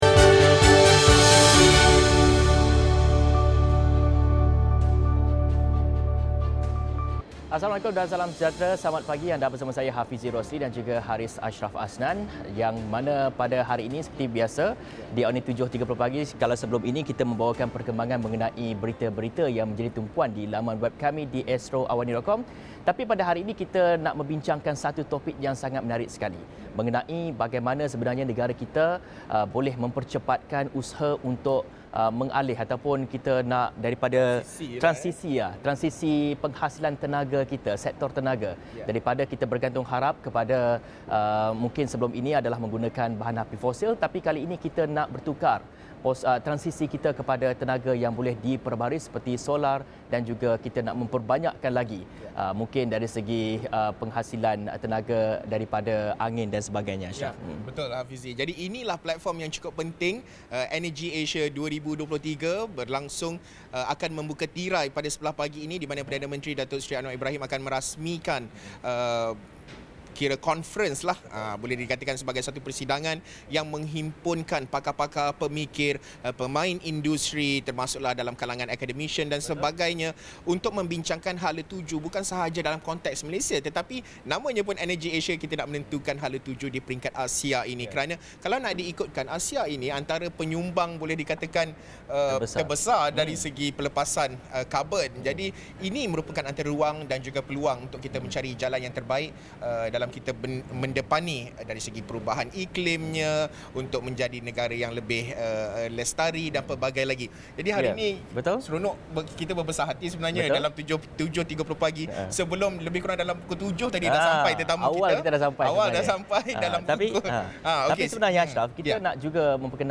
Langsung dari KLCC sempena Energy Asia 2023